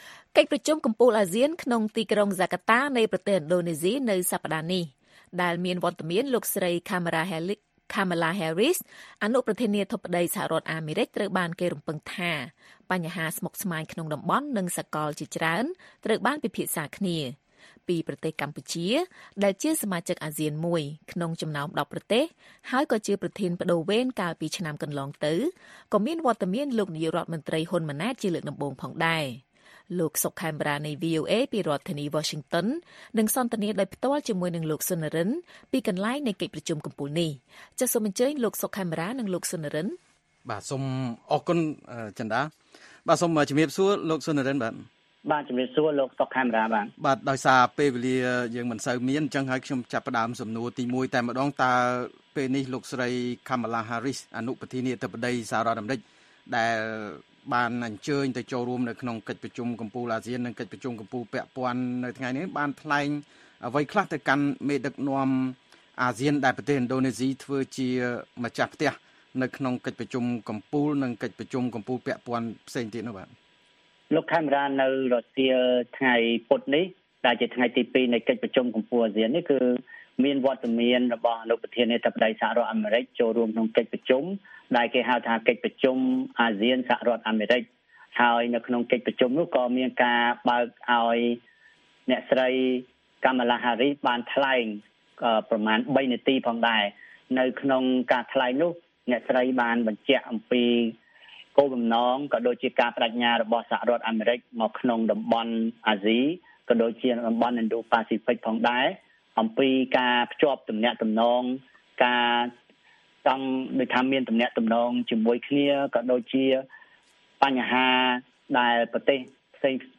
បទសន្ទនាវីអូអេ៖ អនុប្រធានាធិបតីអាមេរិកចូលរួមកិច្ចប្រជុំកំពូលអាស៊ាននៅឥណ្ឌូណេស៊ី